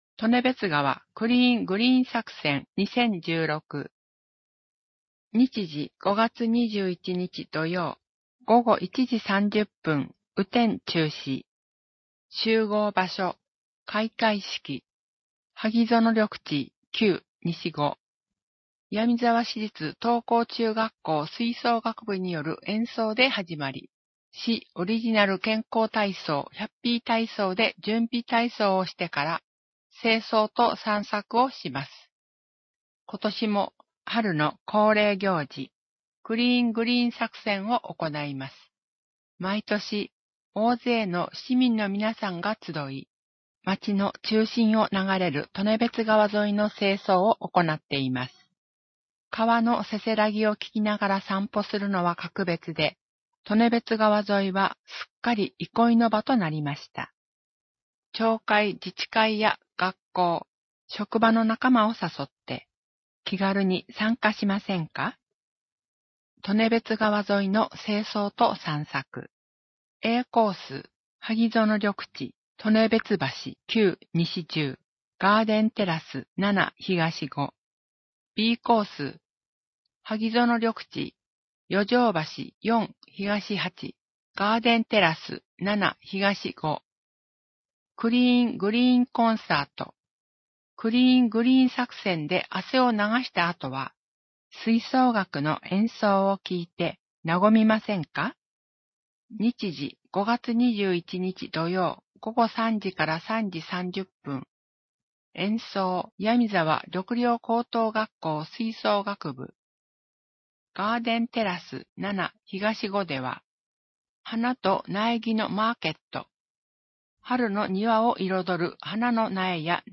声の広報（MP3）